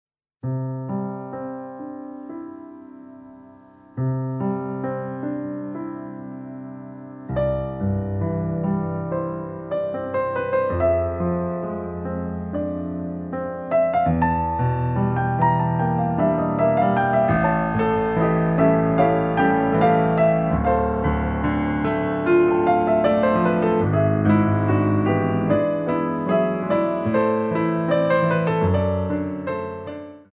Compositions for Ballet Class
Pliés